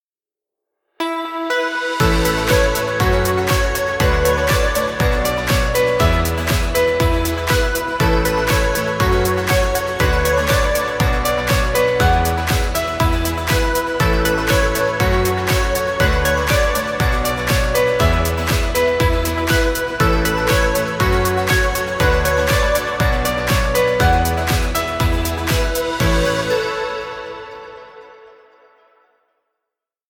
cheerful motivational music.
Positive corporate music for video.
Background Music.